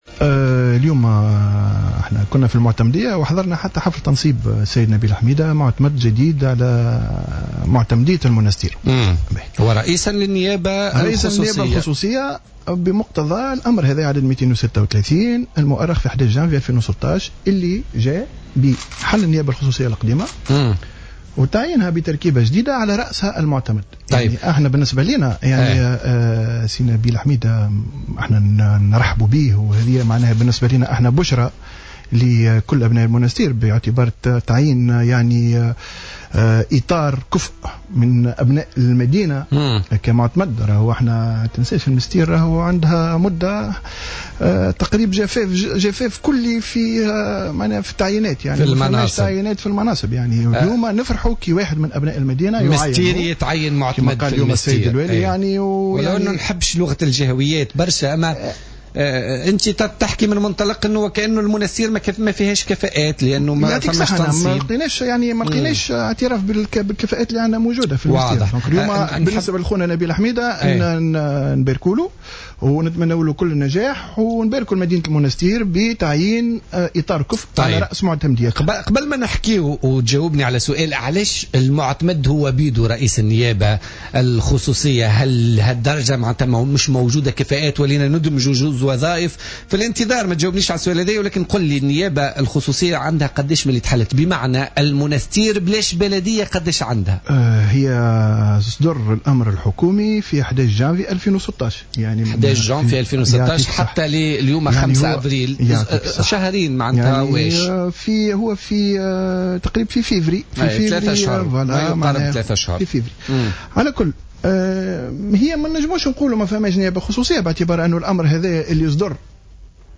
في مداخلة له اليوم